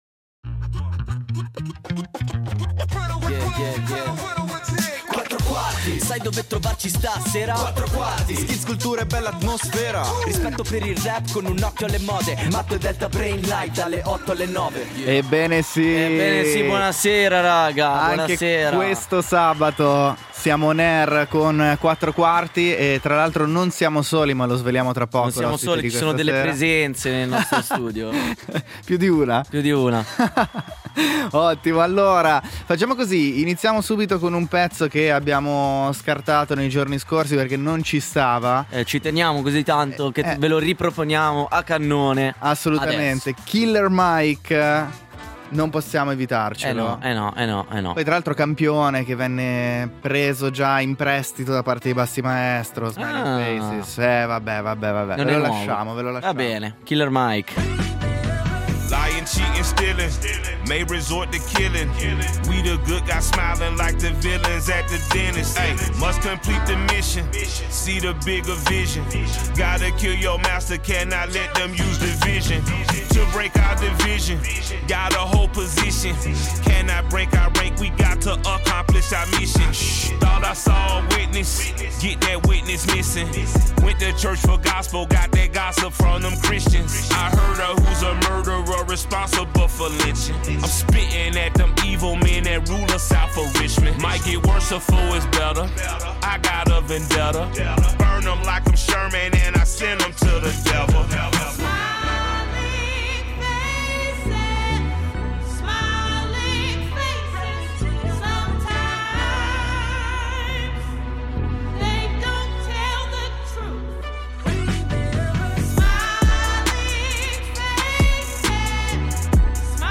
Quattro Quarti Intervista